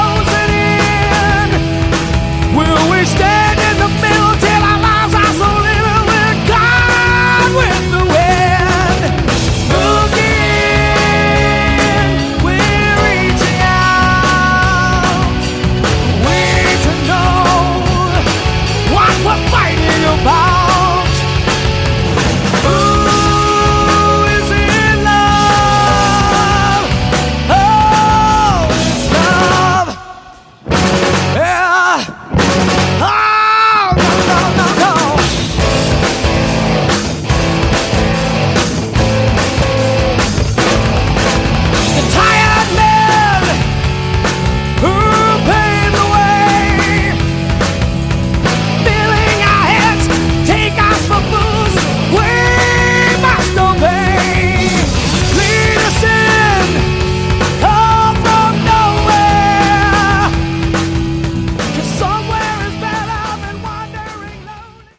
Category: Hard Rock
lead vocals, additional guitar
guitar
drums, vocals
bass, vocals
keyboards, vocals